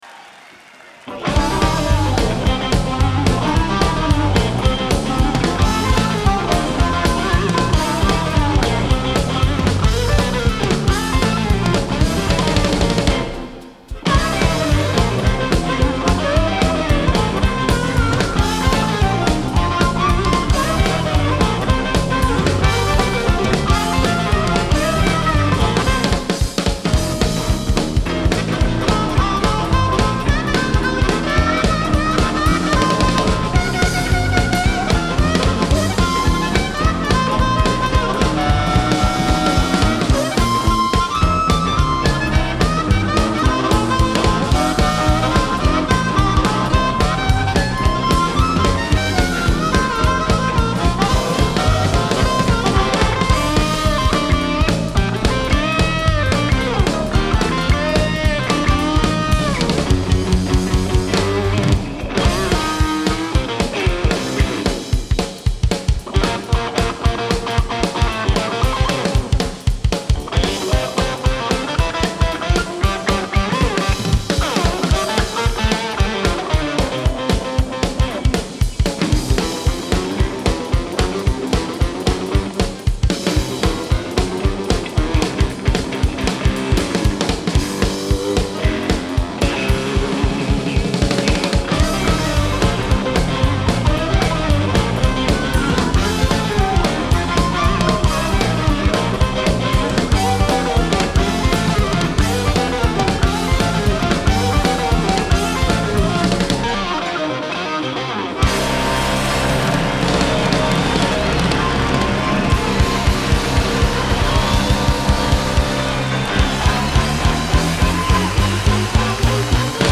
Genre : Blues
Live At The Town & Country Club, London, UK